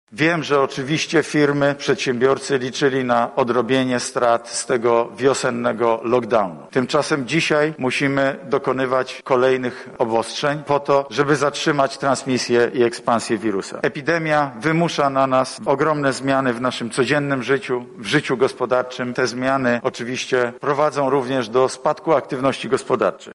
Spójna i dobra polityka finansowa i gospodarcza może być wraz z właściwym zachowaniem ze strony społeczeństwa najlepszą odpowiedzią na pandemię – mówi premier Mateusz Morawiecki